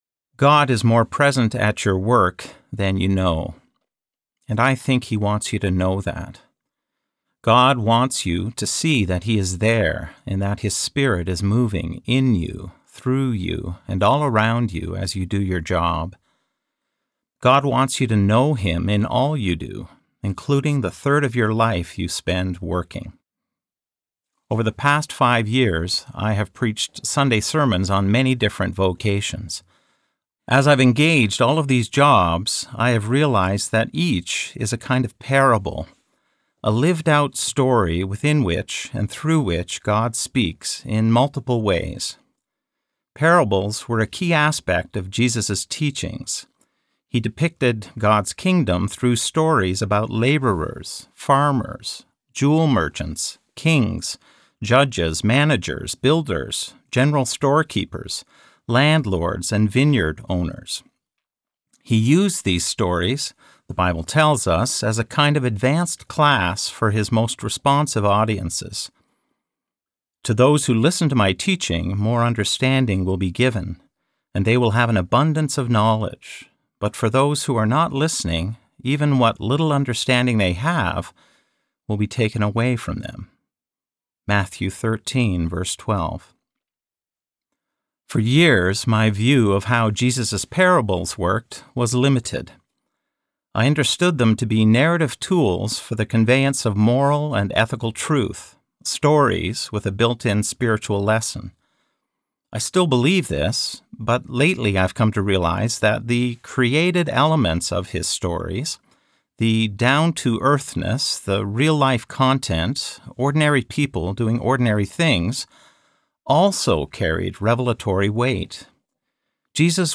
Every Job a Parable Audiobook
Narrator